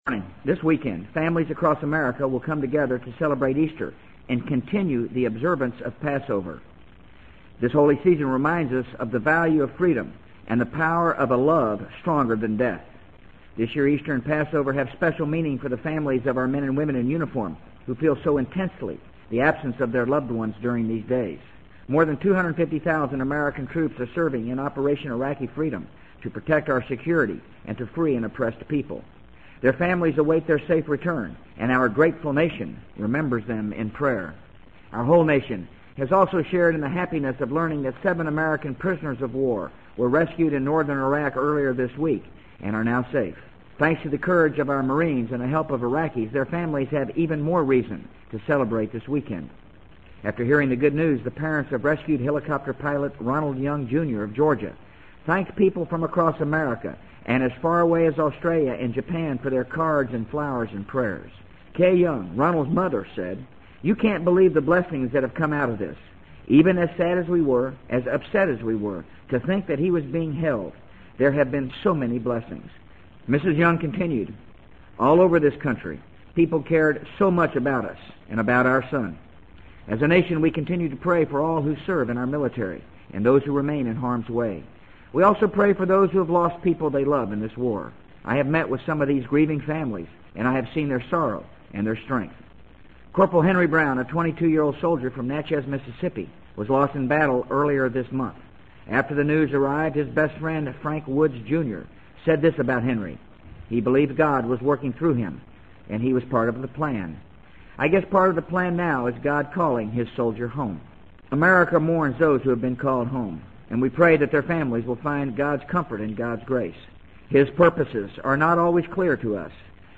【美国总统George W. Bush电台演讲】2003-04-19 听力文件下载—在线英语听力室